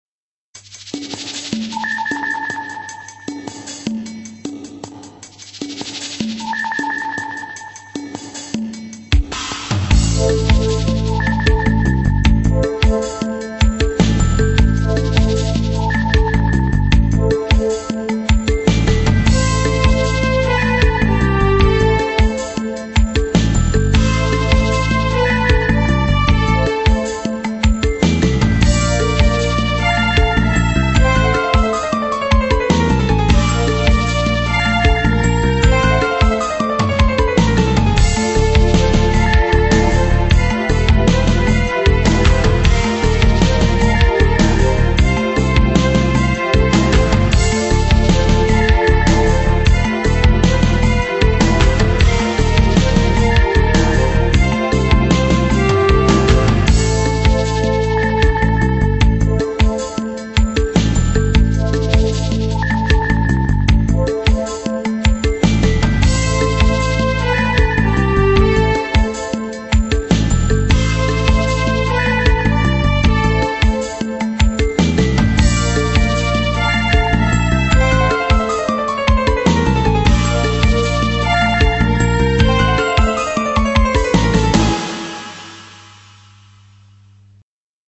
音質下げてます、ご了承下さい。
「悪巧み、暗躍」シーン。主に暗躍の方をイメージ。